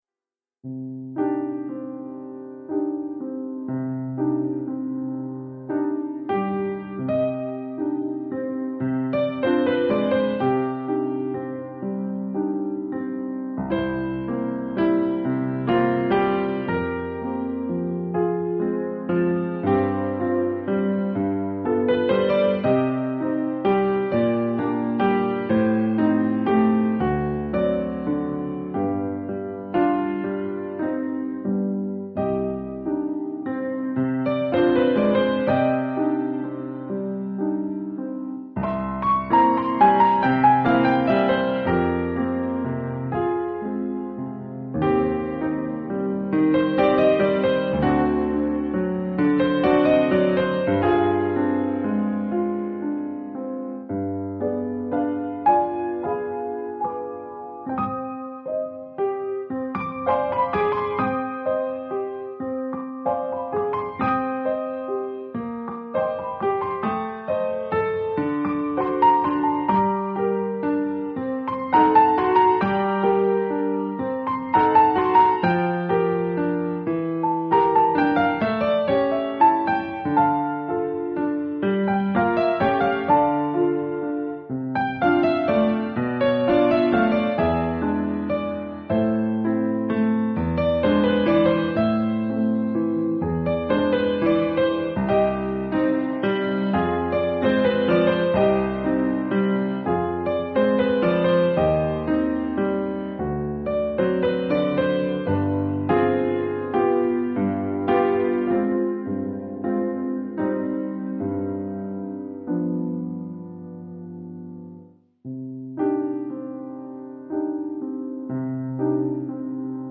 1 titre, piano solo : partie de piano
Oeuvre pour piano solo.